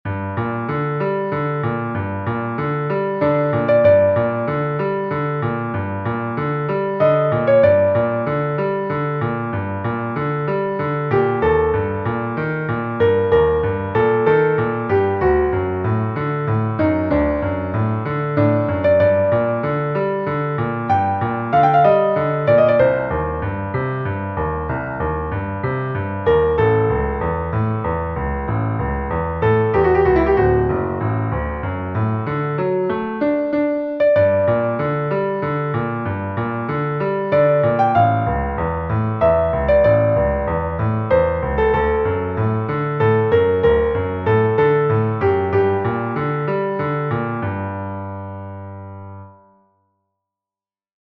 Two Preludes - G minor and E flat minor - Piano Music, Solo Keyboard
The G minor was written improvised and bears some resemblance to the Chopin Prelude in E minor.